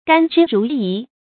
gān zhī rú yí
甘之如饴发音